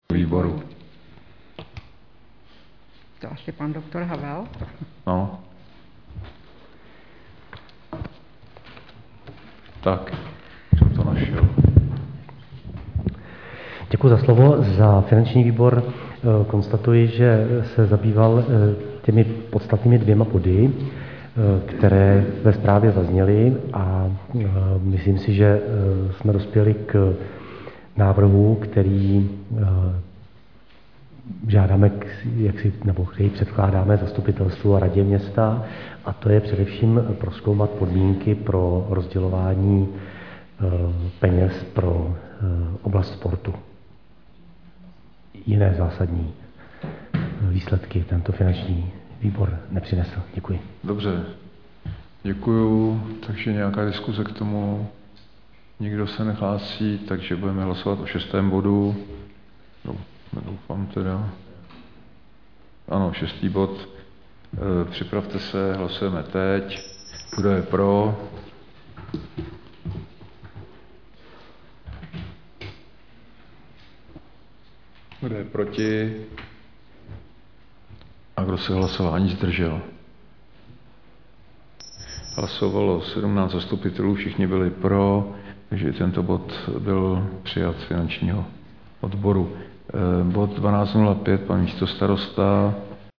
Usnesení Zastupitelstva č.12 ze dne 23.Červen 2021
Záznam jednání: